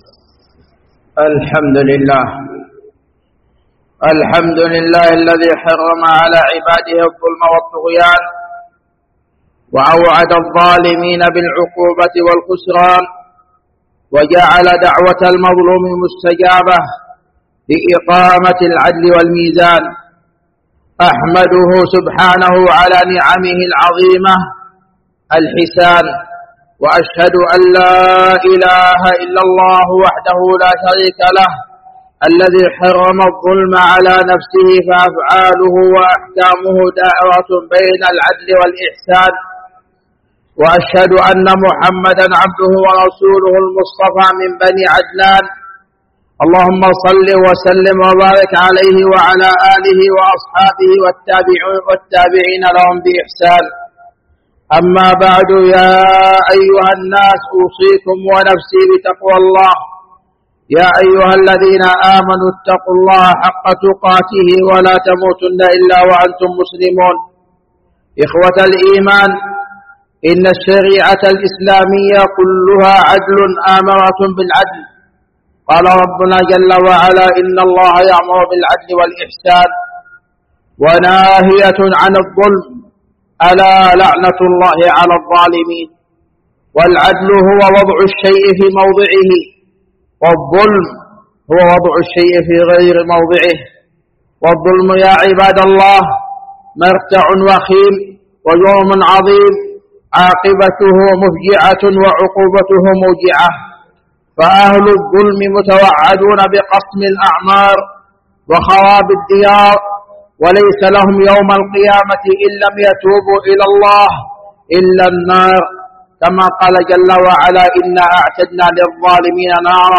جامع الملك عبدالعزيز باسكان الخارش بصامطة
سماع الخطبة